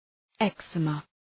Shkrimi fonetik{ıg’zi:mə, ‘eksəmə}